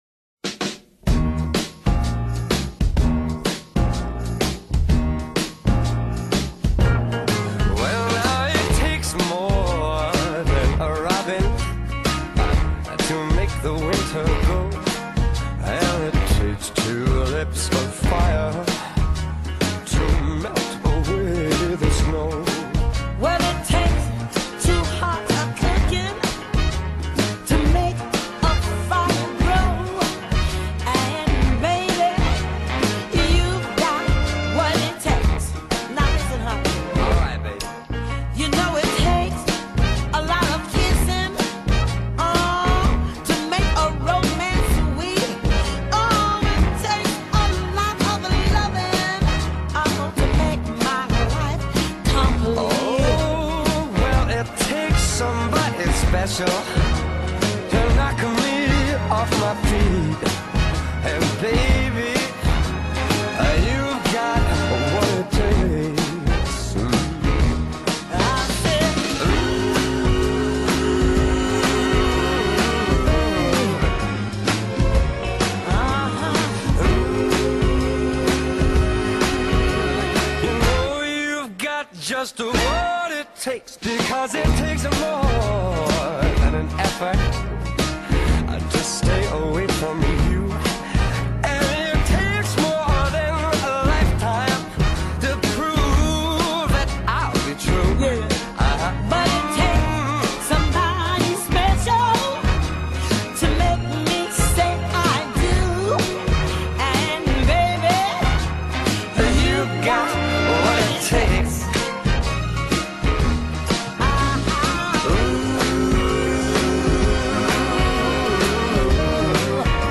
Vocal Duet und Big Band